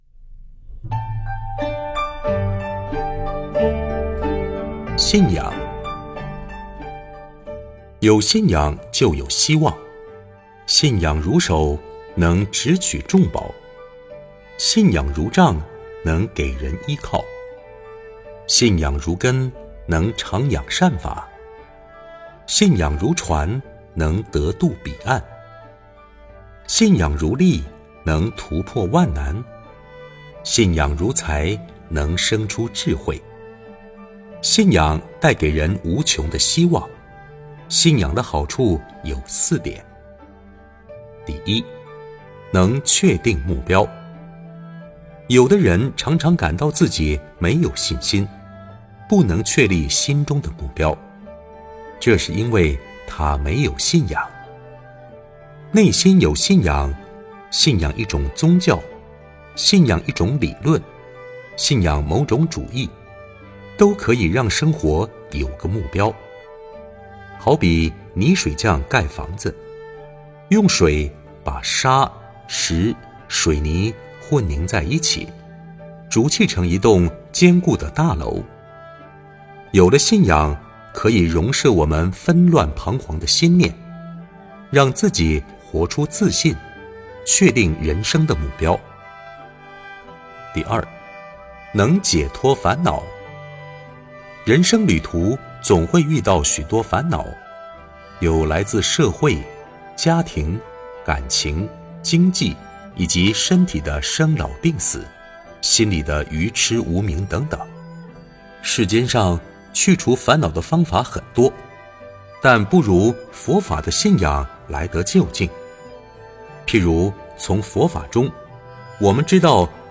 信仰--佚名 点我： 标签: 佛音 冥想 佛教音乐 返回列表 上一篇： 20.